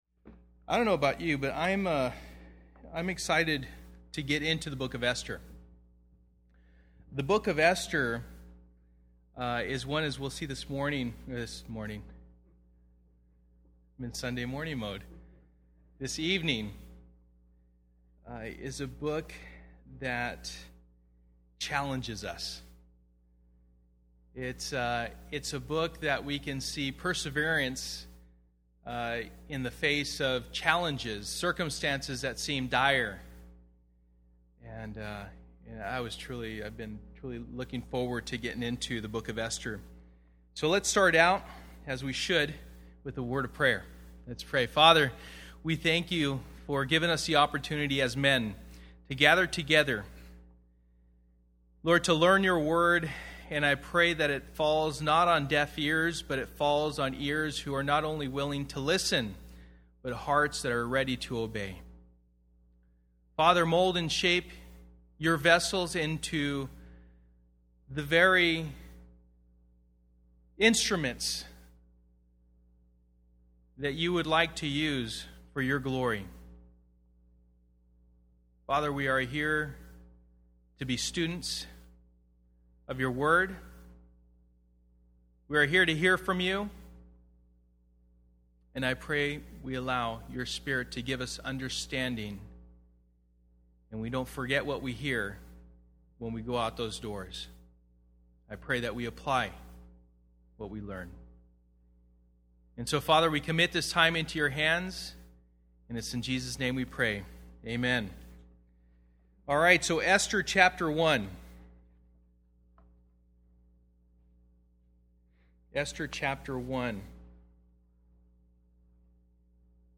Esther Passage: Esther 1:1-10:3 Service: Men's Bible Fellowship %todo_render% « Sold Out